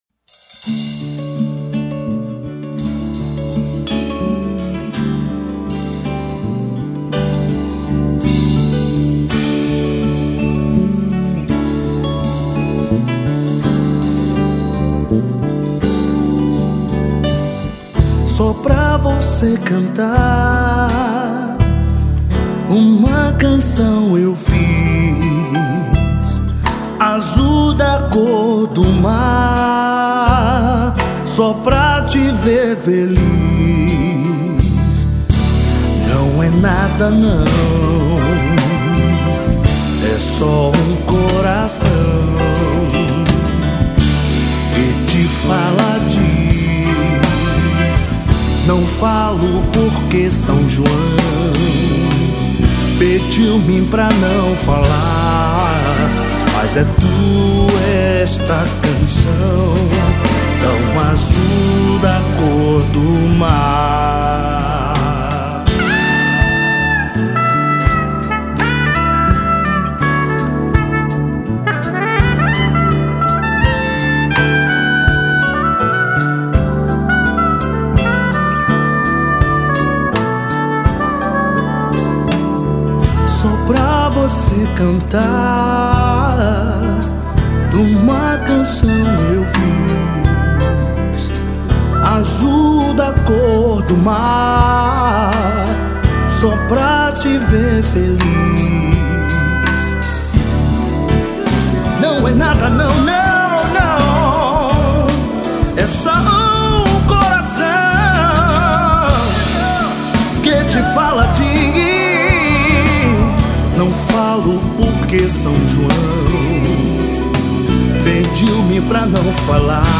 57   03:58:00   Faixa:     Canção
Voz
Violao Acústico 6
Teclados
Efeitos Musicais
Baixo Elétrico 6
Bateria
Percussão
Sax Soprano